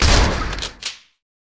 火器 " 半自动步枪射击 1
描述：现场录制步枪＃1。
Tag: 枪械 射击 步枪 射击 武器 FX 镜头